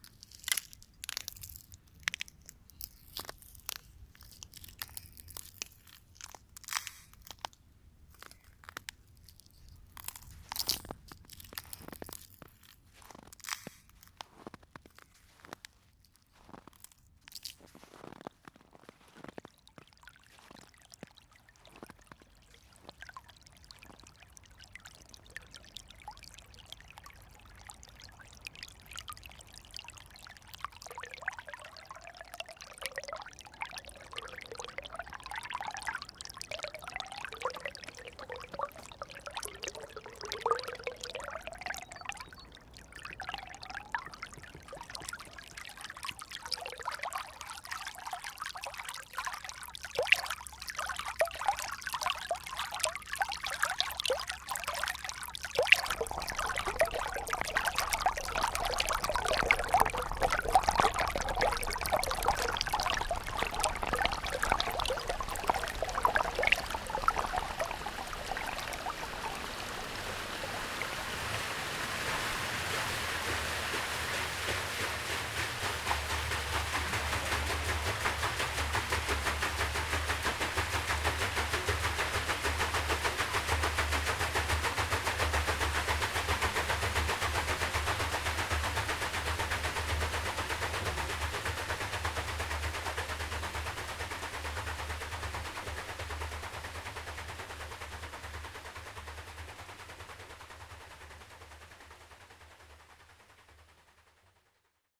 Hörstück ohne Sprache
ZüriWasser ist eine poetische Reise durch die Klanglandschaften von Wasser und der Gewässer von Zürich.
ZüriWasser is a poetic journey through the soundscapes of water and the bodies of water of Zurich. Places, forms and uses of water from the twelve districts of the canton can be imagined and tell of the multi-layered melodies of the powerful element.